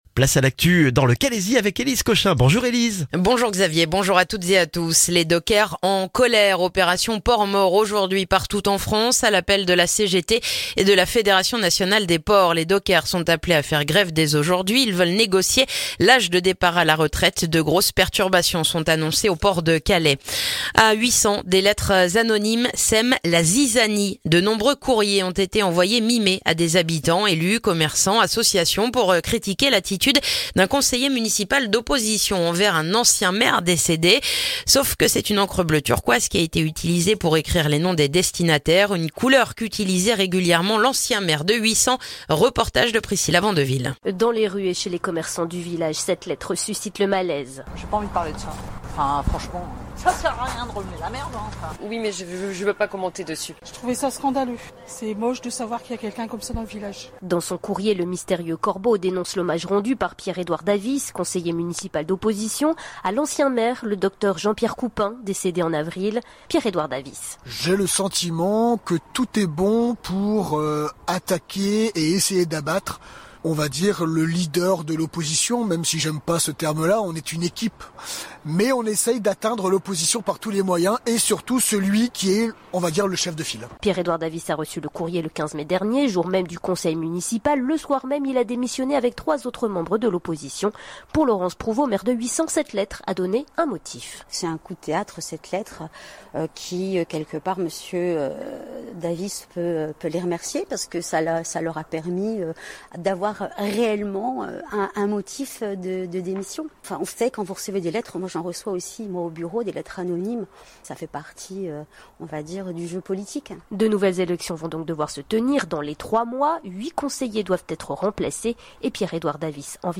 Le journal du vendredi 7 juin dans le calaisis